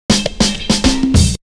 Break 7.wav